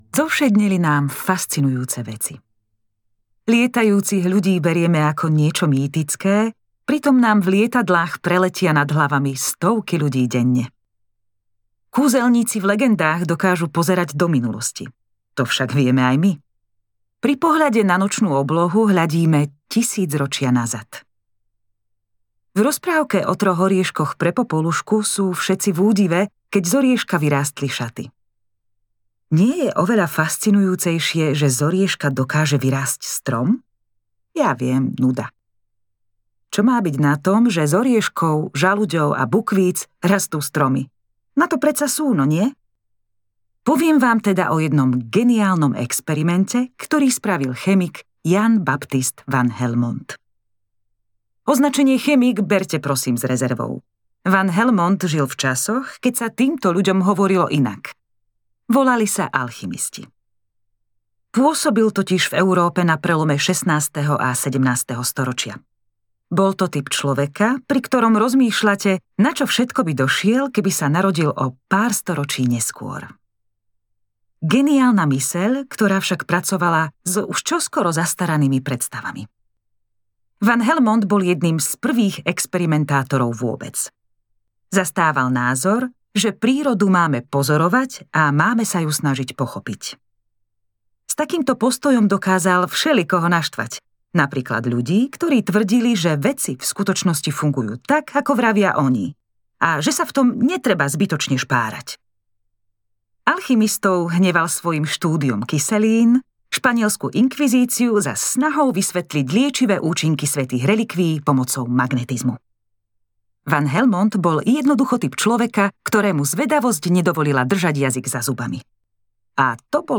Obyčajné zázraky audiokniha
Ukázka z knihy